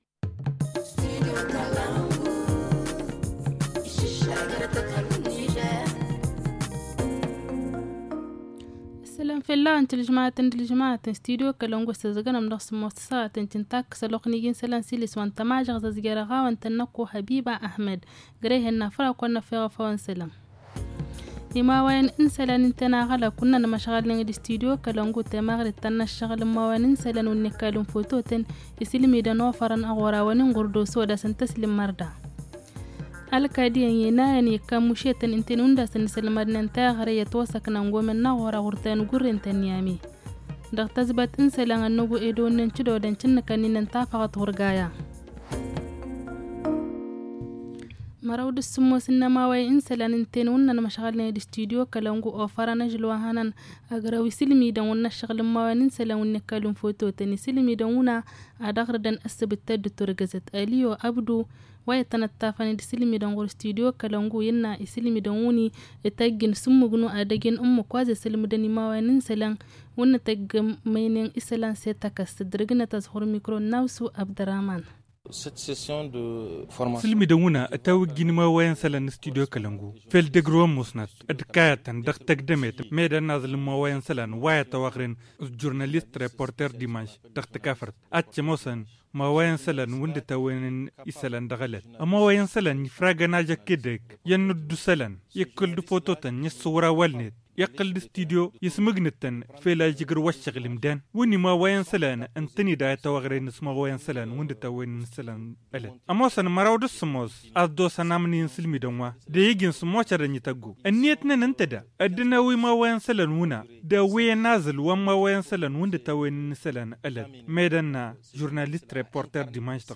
Le journal du 24 mai 2022 - Studio Kalangou - Au rythme du Niger